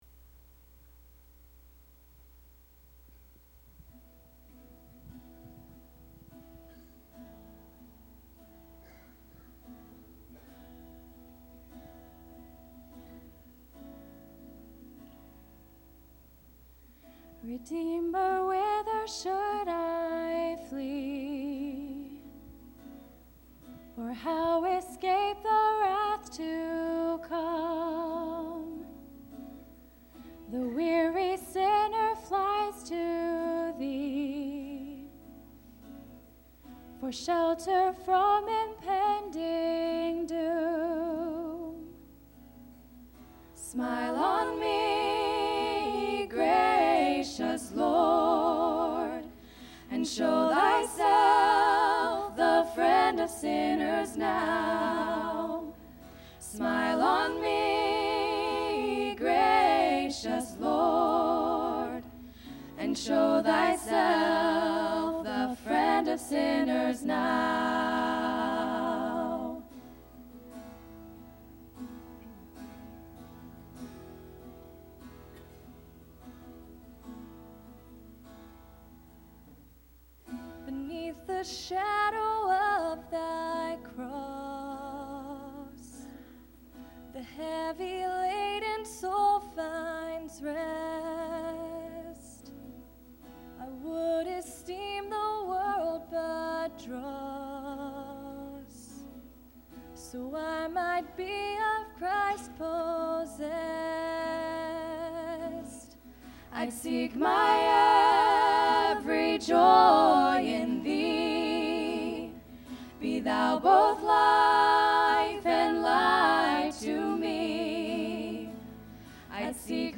I especially enjoy the three ladies singing “Smile On Me Gracious Lord.”